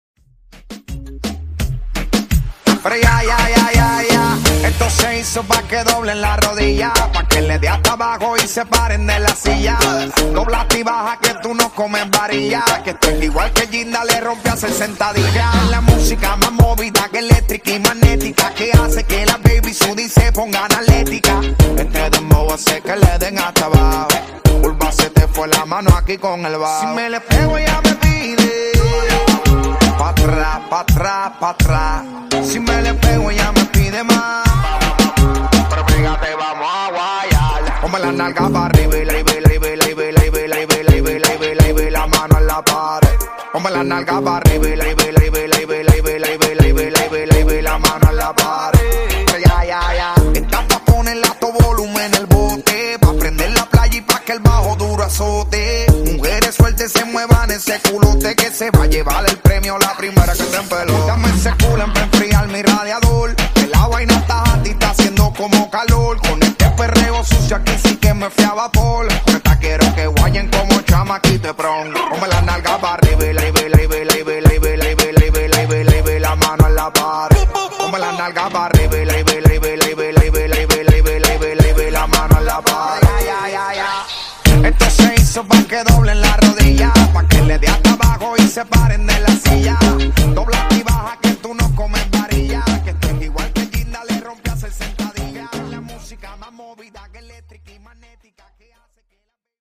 Genre: RE-DRUM Version: Dirty BPM: 131 Time